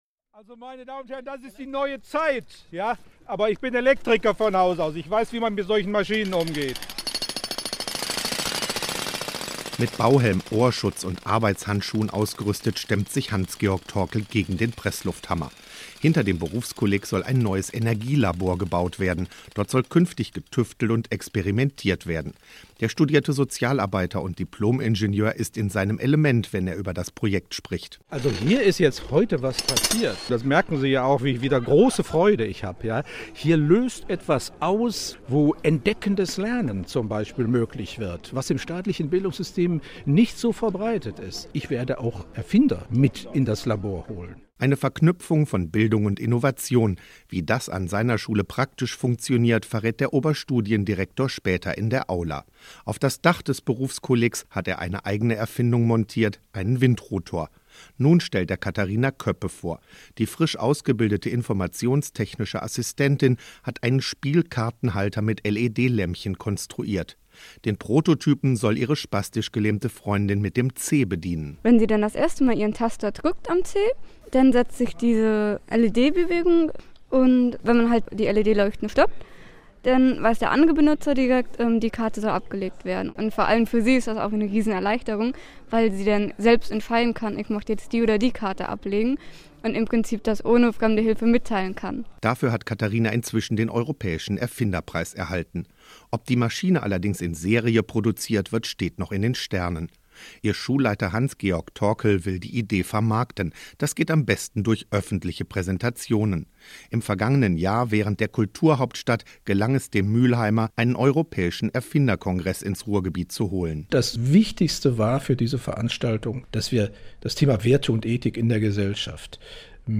Hier ein Kurzporträt aus der WDR 5 – Sendung Thema NRW vom Februar 2011 Erfinderszene NRW